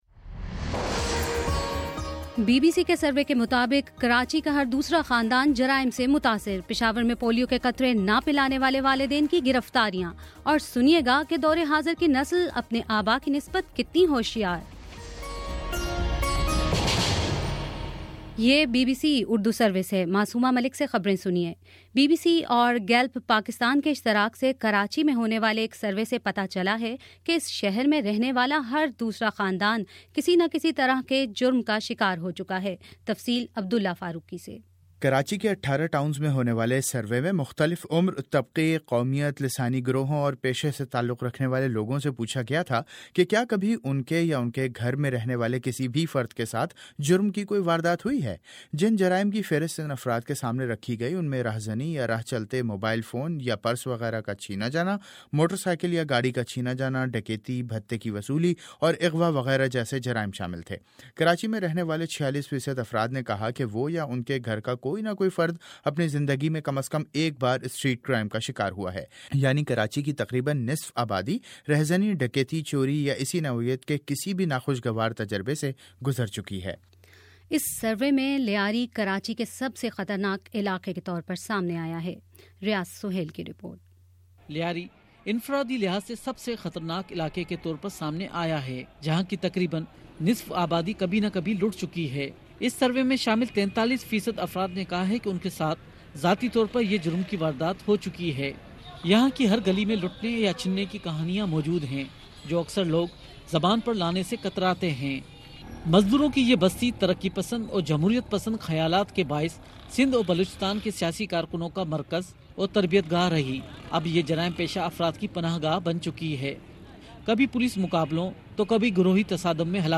مارچ 02 : شام پانچ بجے کا نیوز بُلیٹن